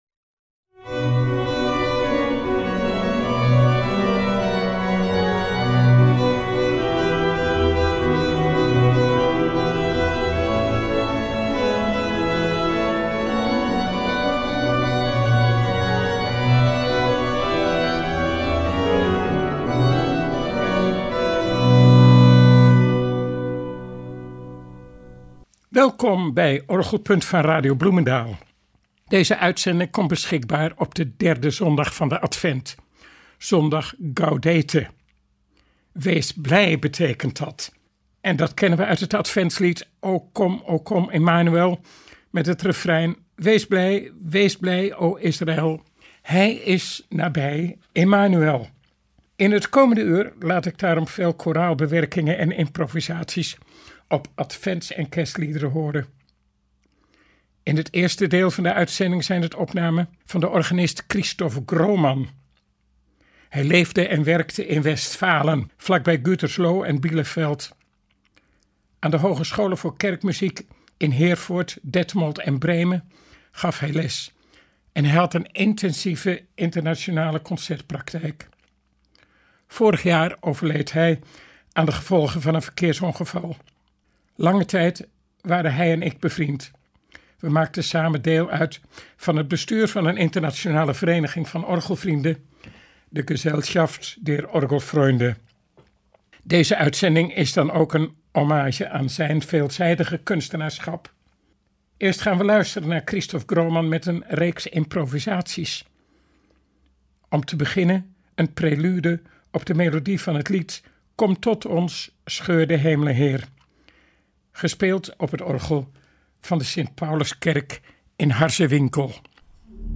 improvisaties over advents- en kerstliederen
Marialiederen